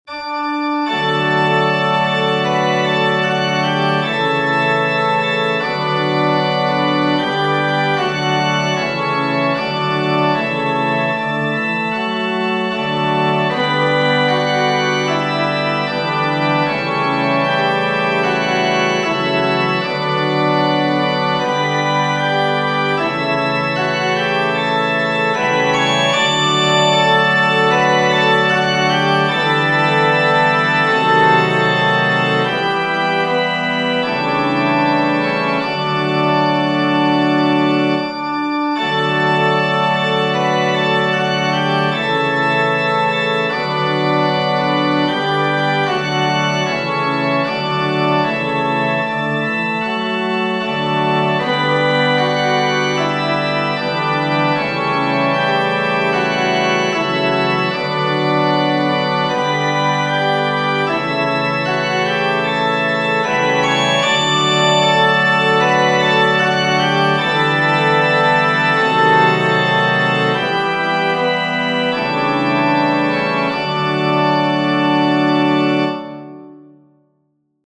Parry, C. H. H. Genere: Religiose Text by John Greenleaf Whittier Dear Lord and Father of mankind, Forgive our foolish ways!